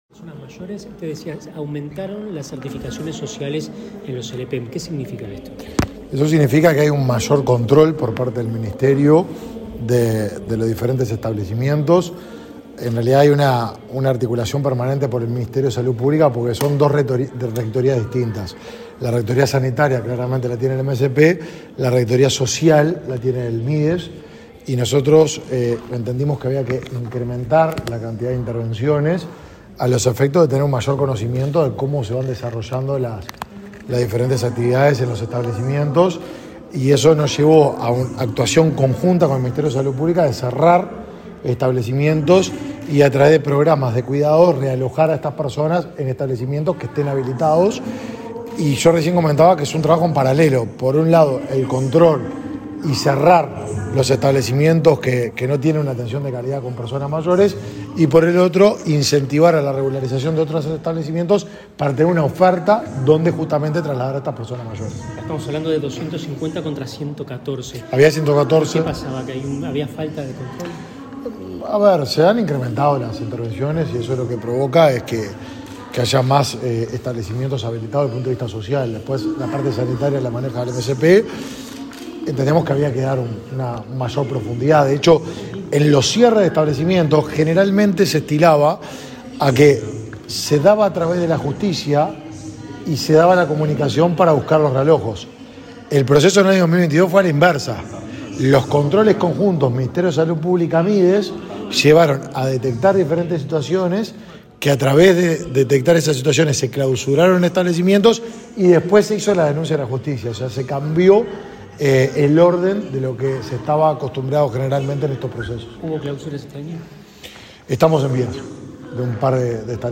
Declaraciones del ministro de Desarrollo Social, Martín Lema
Declaraciones del ministro de Desarrollo Social, Martín Lema 29/09/2023 Compartir Facebook X Copiar enlace WhatsApp LinkedIn Este viernes 29, el ministro de Desarrollo Social, Martín Lema, participó, en el Palacio Legislativo, en el XI Encuentro Nacional de las Personas Mayores. Luego dialogó con la prensa.